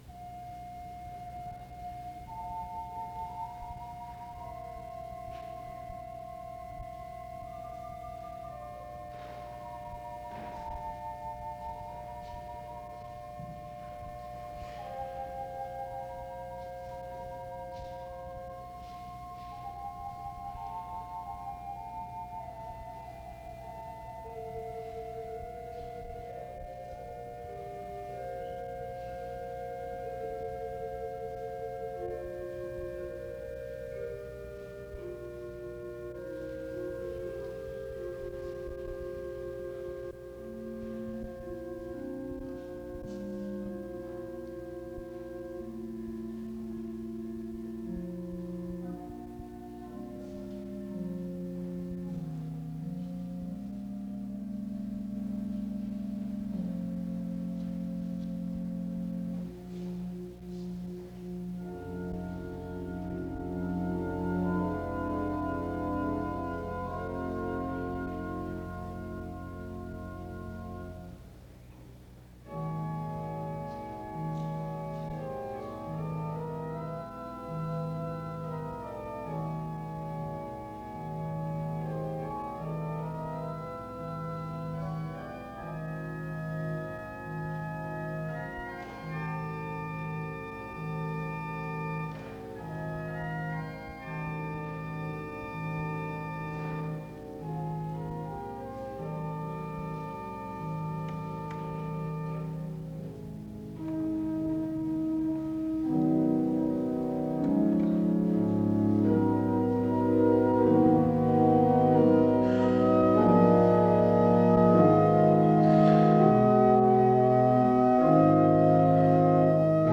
The service begins with music from 0:00-3:59. A portion of Hebrews 12 is read from 4:10-4:43. More music plays from 4:49-10:02.
Psalm 8 is read from 10:10-11:32.
A prayer is offered from 13:03-14:56.
Closing music plays from 30:26-35:18.
Wake Forest (N.C.)